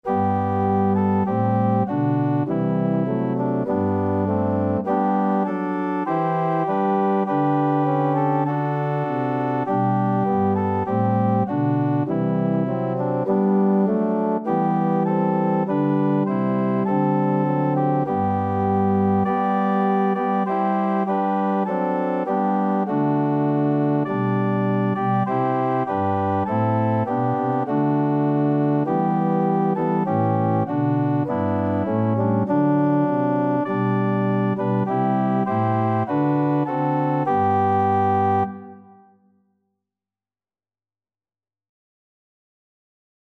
Free Sheet music for Organ
4/4 (View more 4/4 Music)
G major (Sounding Pitch) (View more G major Music for Organ )
Organ  (View more Easy Organ Music)
Classical (View more Classical Organ Music)